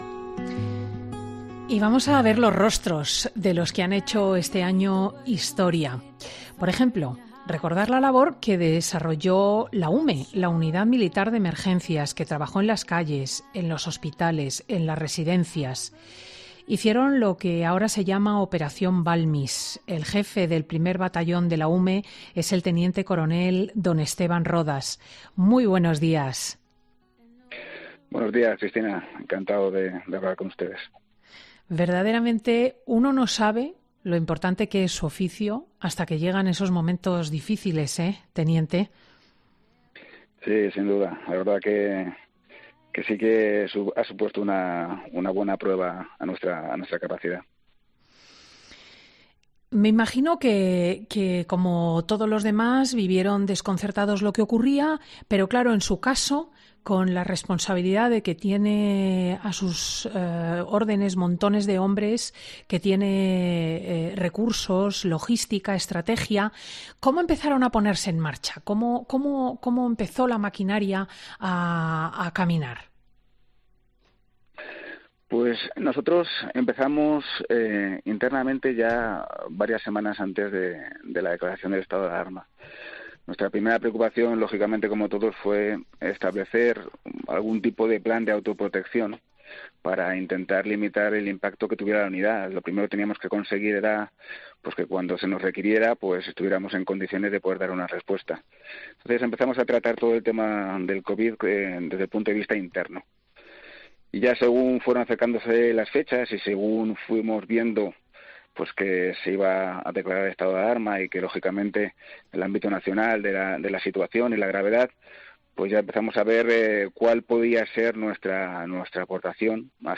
“Ha supuesto una buena prueba a nuestra capacidad”, reconoce en los micrófonos de Fin de Semana con Cristina López Schlichting.